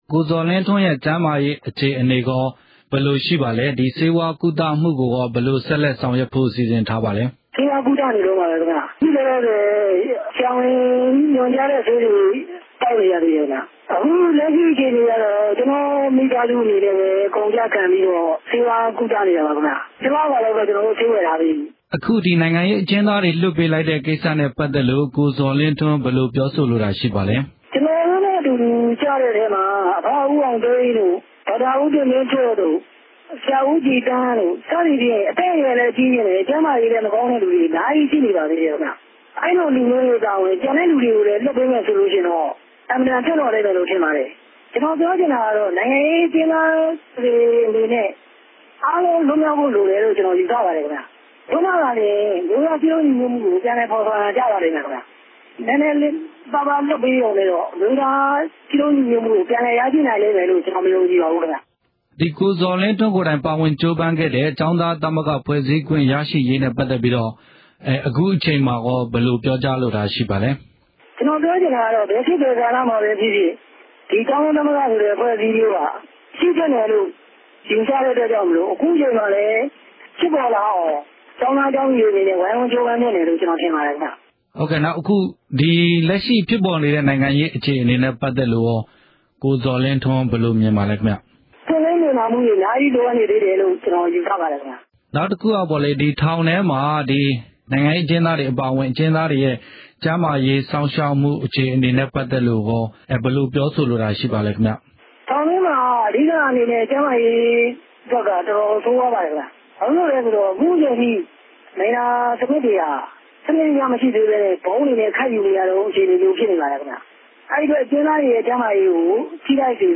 ဆက်သွယ်မေးမြန်းချက်။